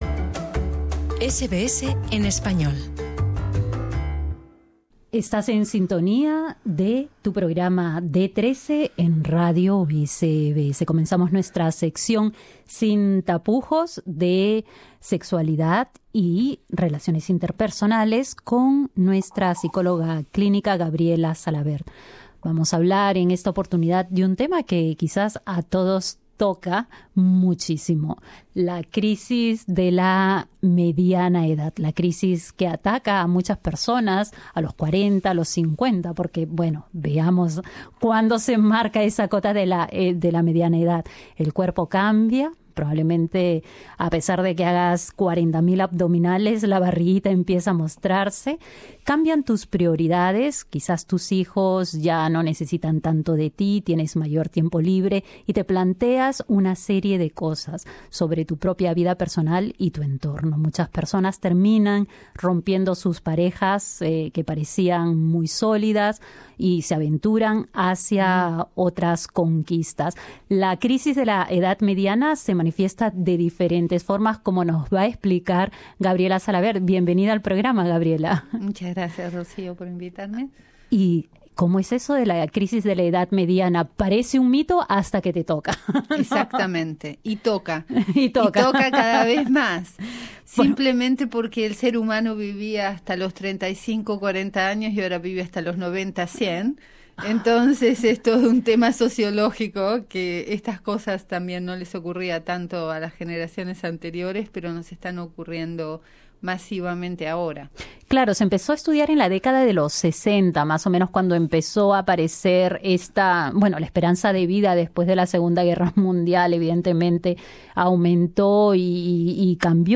La crisis de la edad madura es una etapa por la que pasan muchas personas cuando llegan a los cincuenta. La expectativa de vida es cada vez mayor y el ser humano entra por distintas dinámicas como pueden ser los cambios físicos, el abandono de los hijos del hogar y giros en lo laboral. Dialogamos con la psicóloga clínica